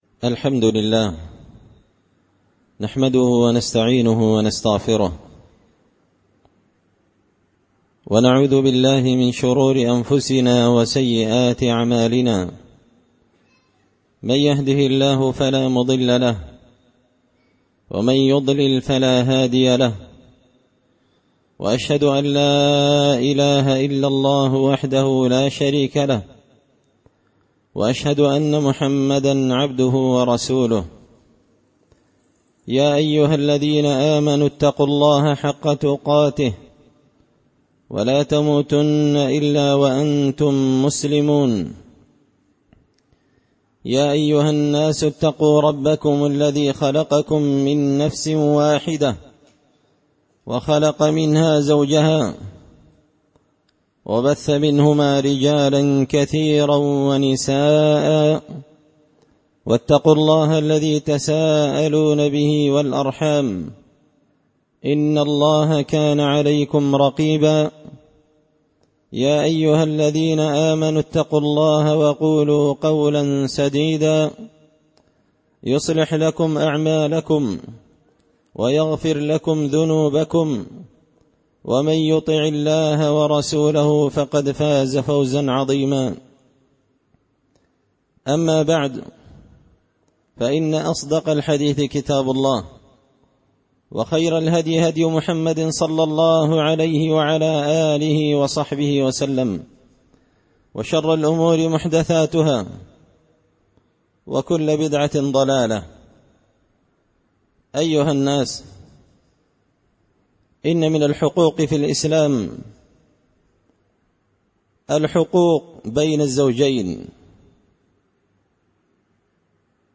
خطبة جمعة بعنوان – حق الزوجين الجزء الأول
دار الحديث بمسجد الفرقان ـ قشن ـ المهرة ـ اليمن